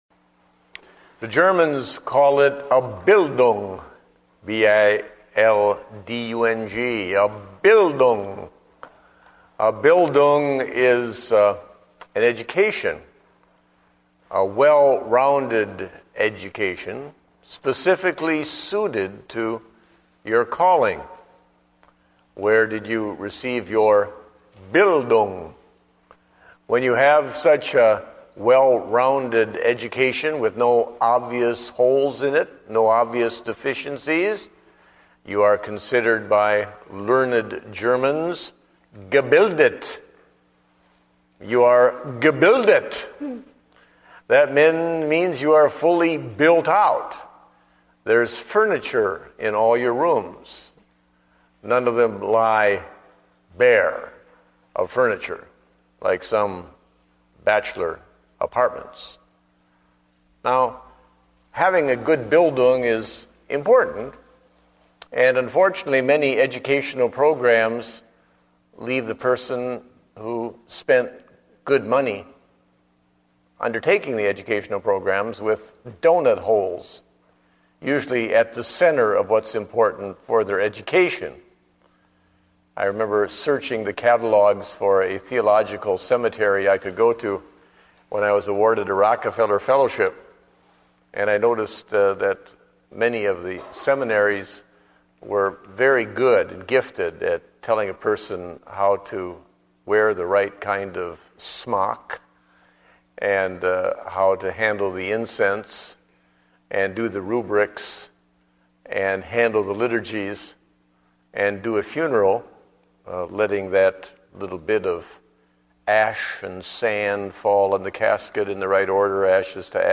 Date: July 12, 2009 (Evening Service)